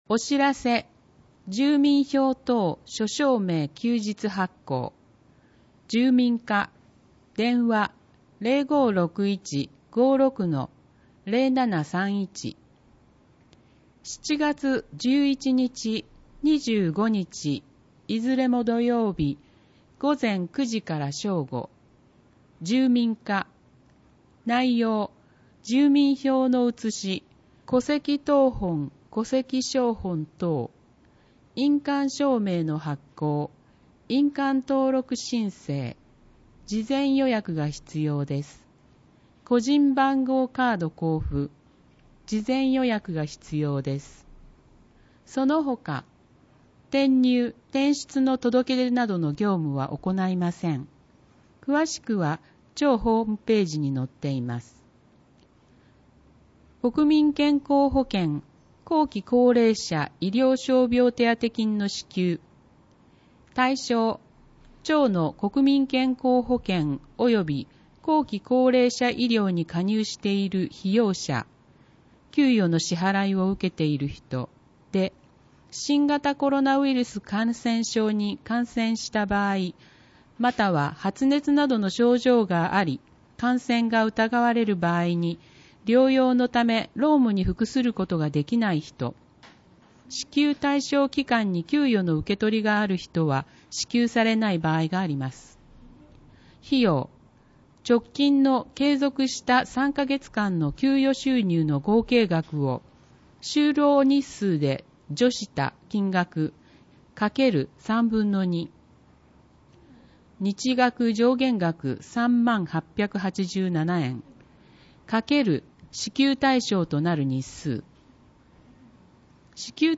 広報とうごう音訳版（2020年7月号）